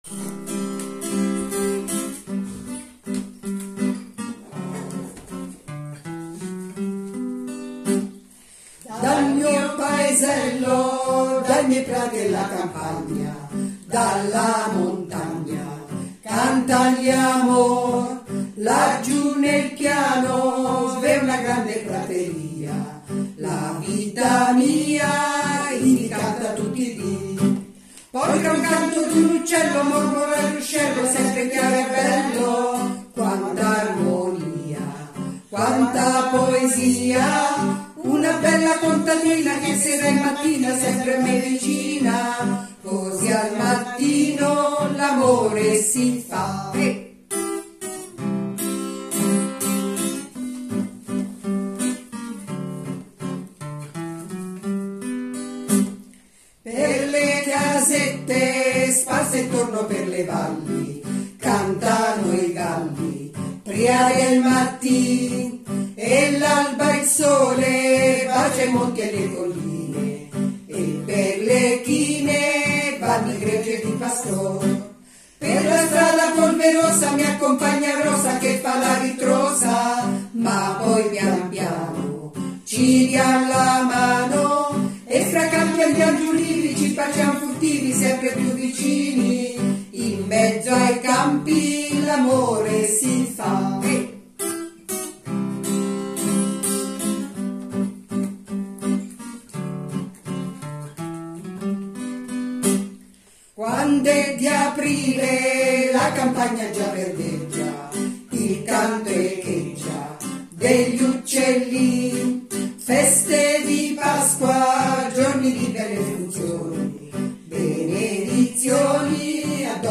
Il gruppo che recita le poesie in dialetto e che canta le due canzoni è composto da
chitarra